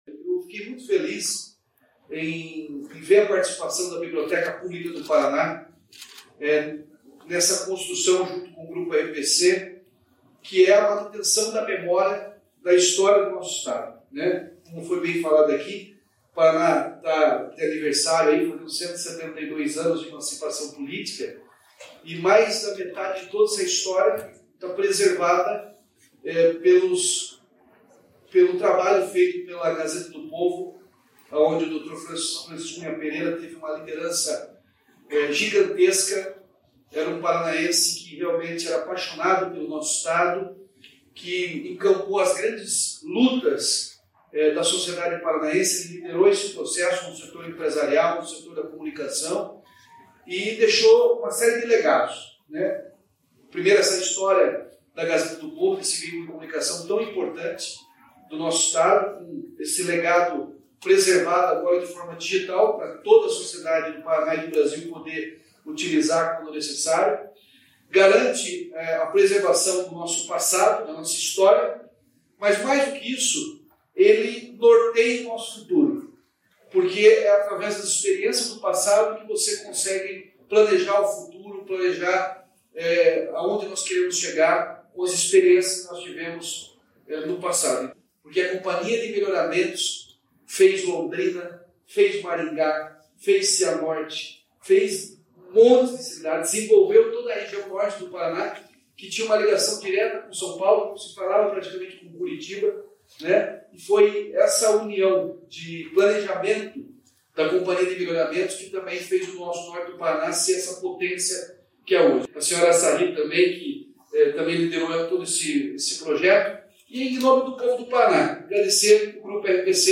Sonora do governador Ratinho Junior sobre o lançamento do projeto Memória Paraná